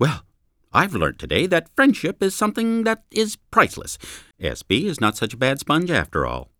SBSPSS / data / Streams / Speech / Eng / 070.wav